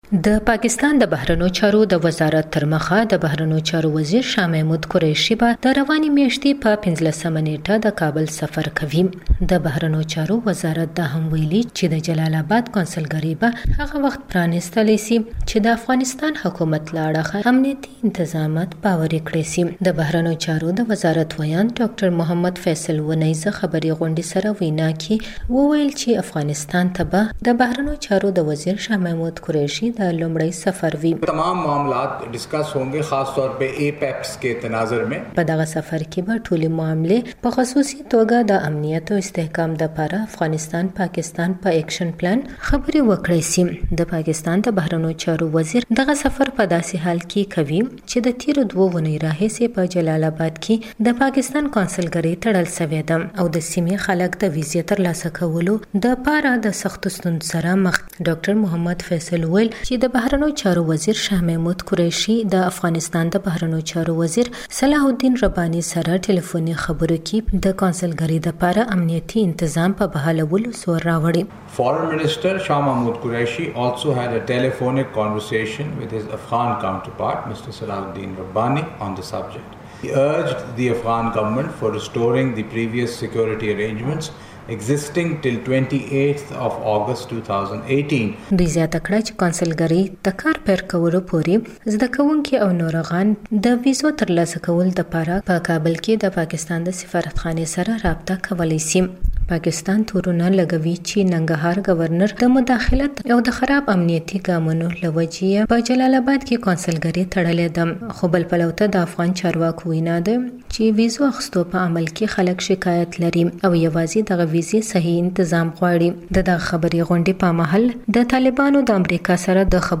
اسلام آباد —